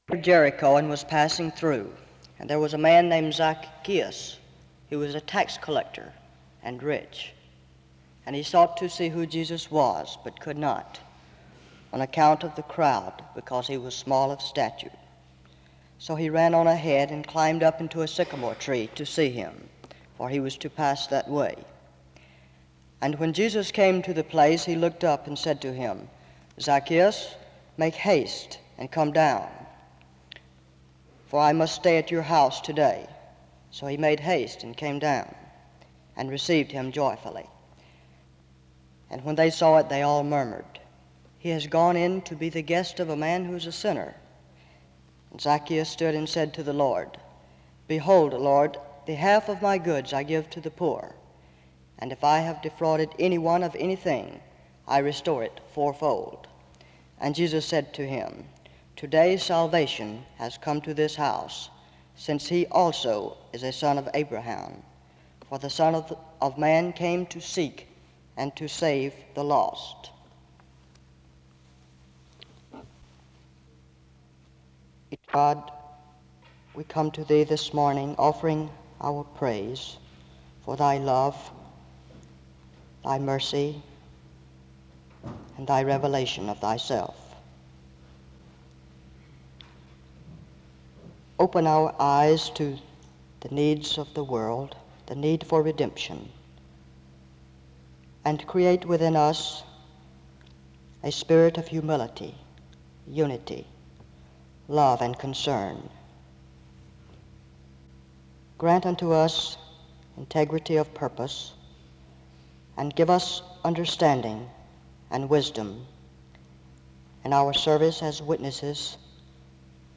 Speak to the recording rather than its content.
He states that the task of the church is to seek those who are lost and bring them to freedom in Christ. He encourages the chapel and reminds them that through the love of Christ we can be selfless. This service was organized by the Student Coordinating Council.